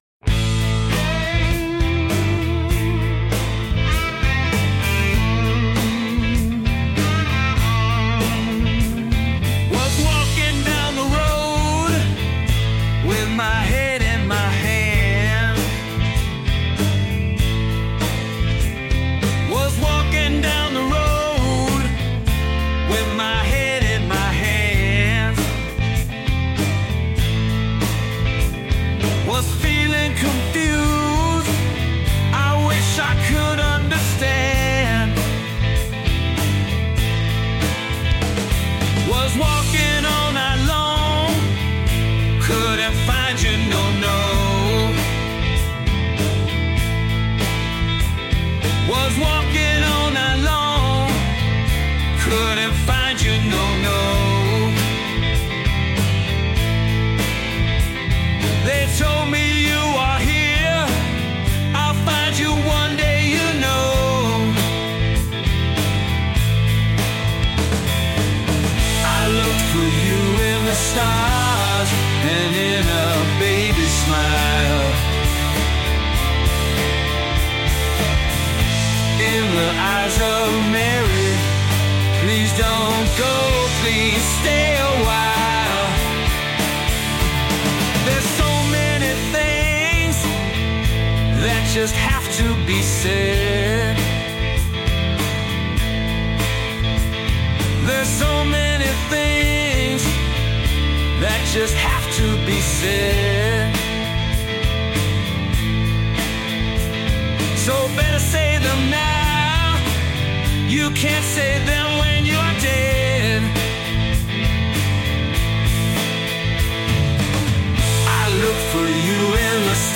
deeply introspective and emotional song